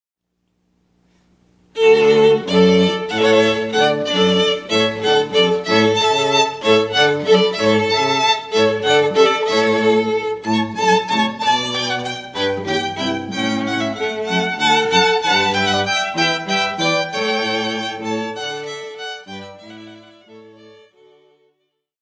Our musicians perform as a String Quartet and a String Trio.